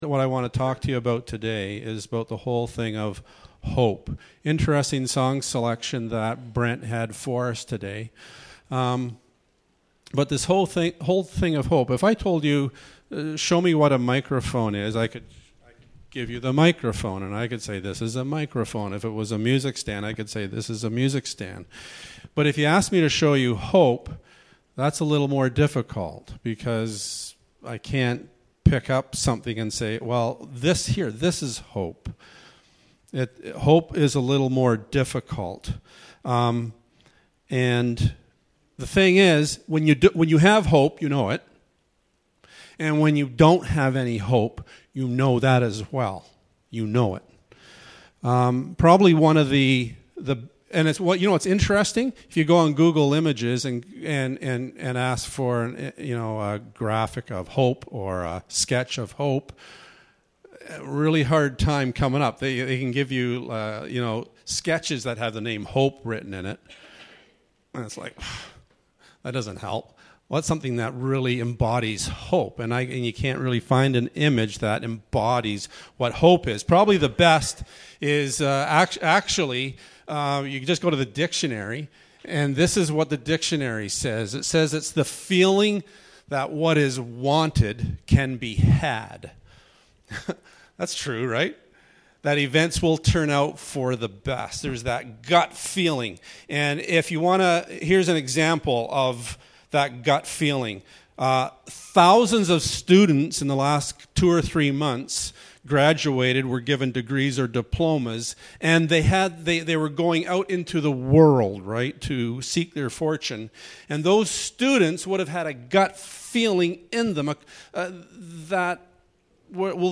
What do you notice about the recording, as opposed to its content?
21-24 Service Type: Sunday Morning Bible Text